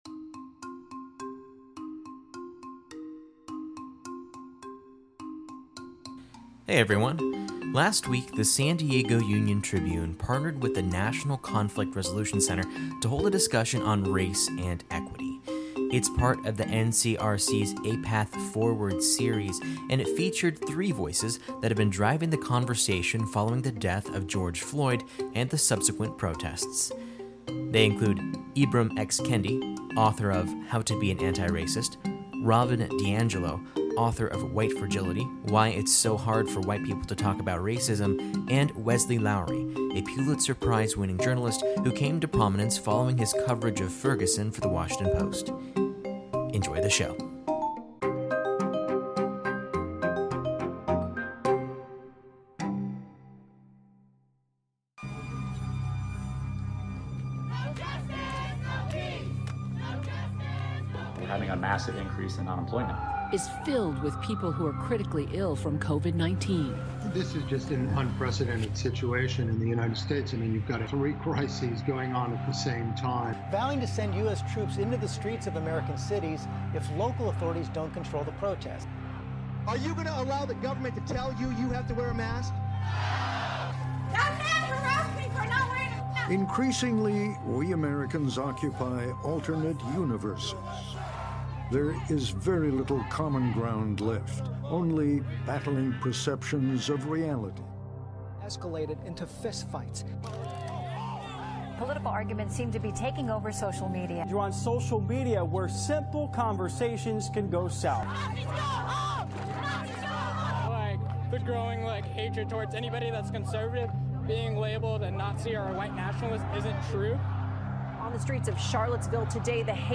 The San Diego Union-Tribune partnered with the National Conflict Resolution Center to hold a discussion on race and equity.